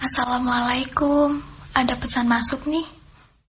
Kategori: Nada dering
Keterangan: Ini adalah suara wanita yang lucu untuk nada dering pesan Anda.